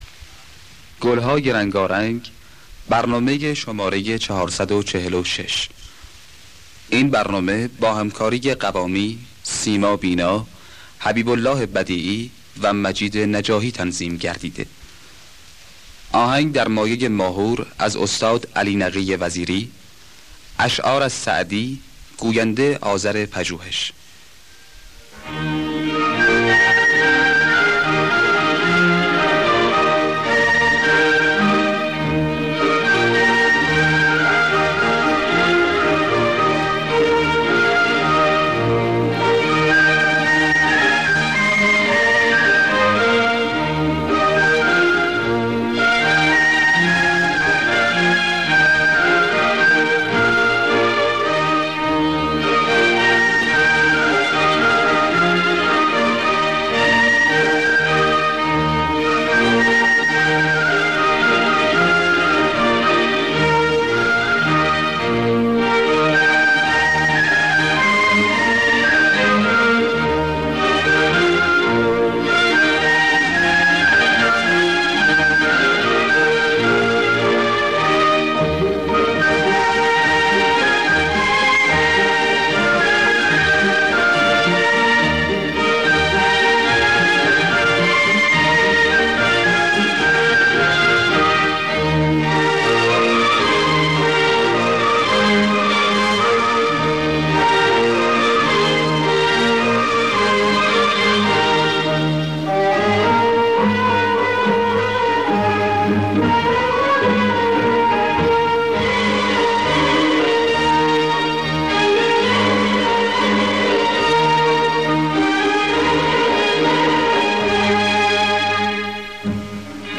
خوانندگان: سیما بینا حسین قوامی